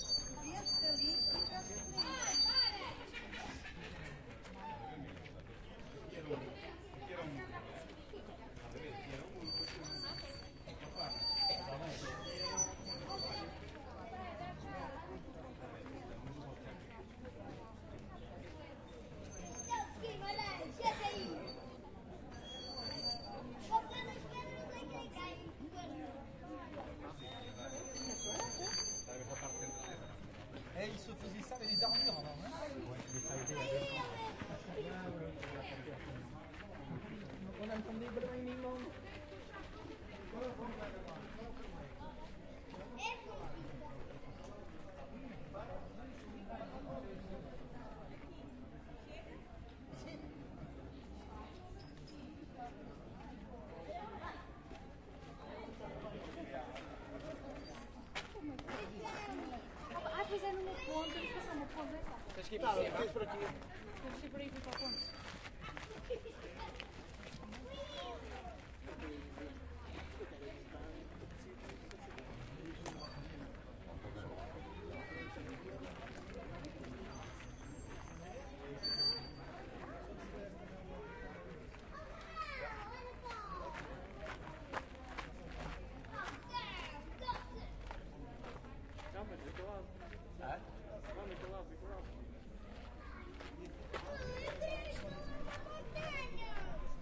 描述：在葡萄牙吉马良斯城堡的一个星期天录制的。旅游者用葡萄牙语交谈，还有很多孩子。场景以口哨开始，在某些时候你可以听到一些步骤。
Tag: 儿童 音景 城堡 步骤 哨子 谈话 现场录音 葡萄牙